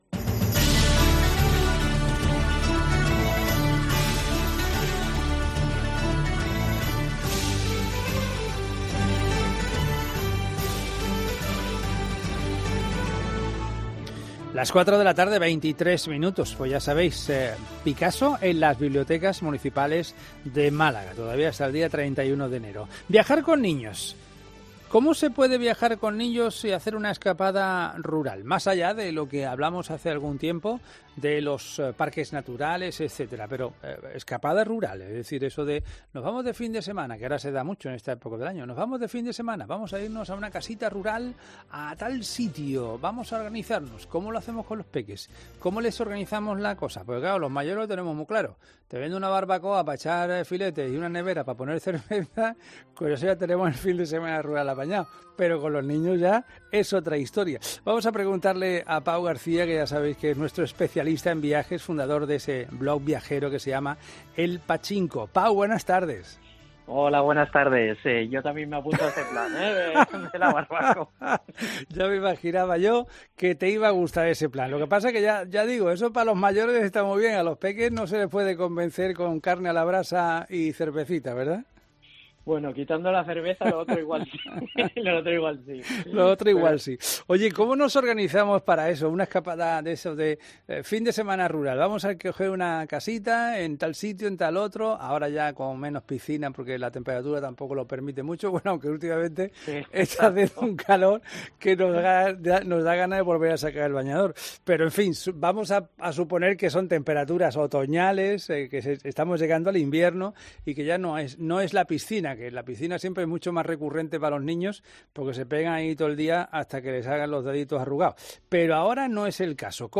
Un especialista en viajes explica en COPE Málaga qué podemos hacer y cuál es la mejor manera para organizarse cuando vamos de escapada con los más pequeños